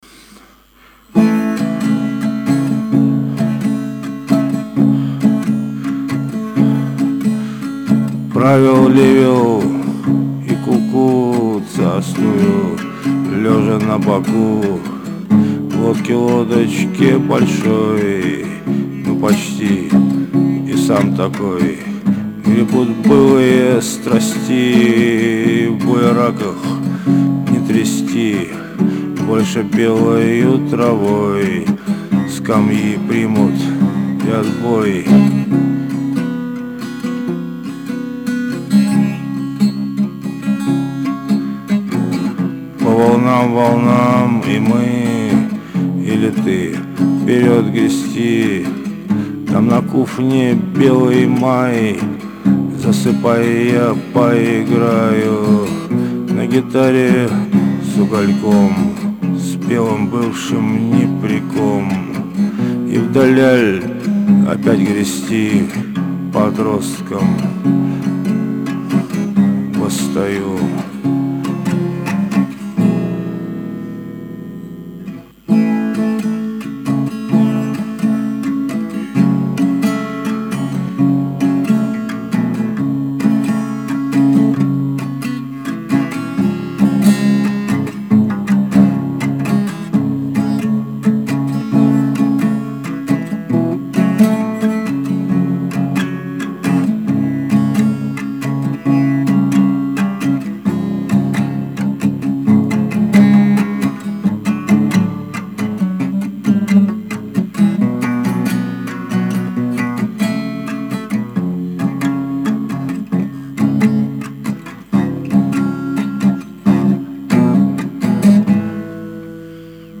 "в куфне" песня, вчера